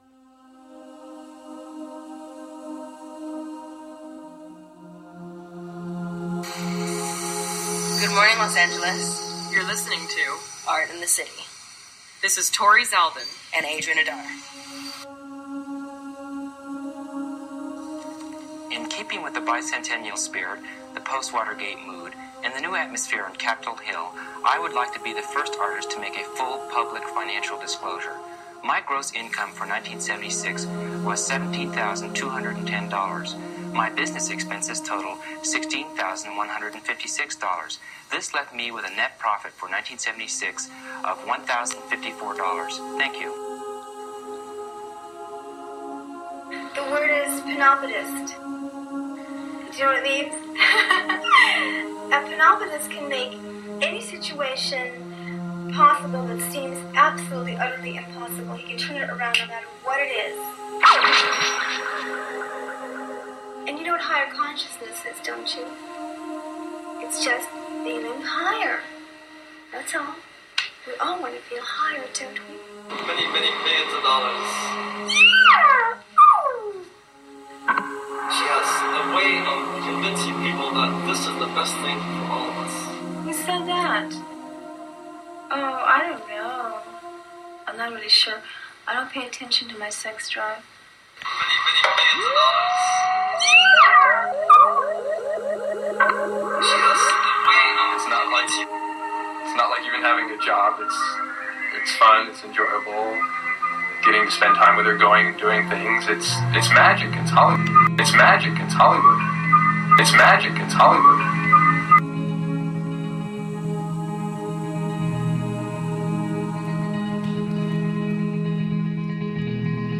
The show focuses on Los Angeles from every decade. We play soundscapes, local music, spoken word, archived recordings, and conduct live interviews with cultural leaders around LA. Ultimately, we are exploring the social and cultural history of the city through sounds and vibrations.
Electronic